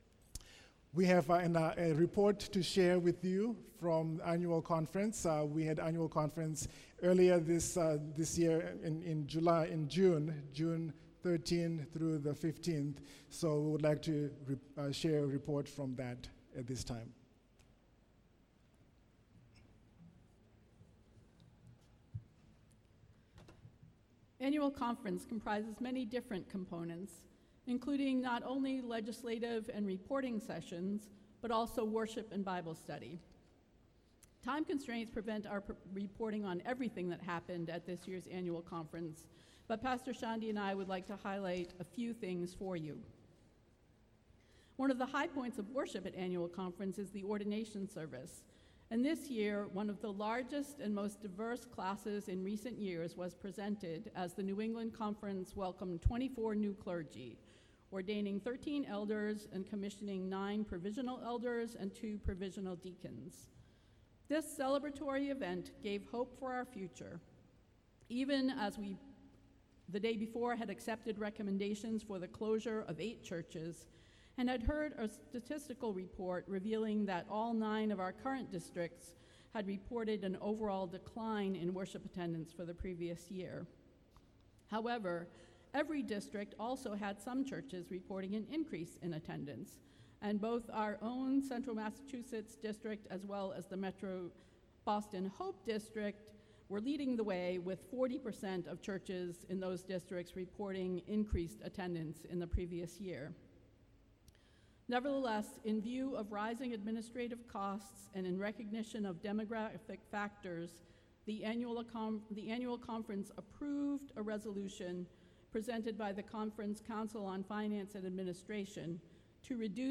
You can hear the audio to the report presented to the congregation below.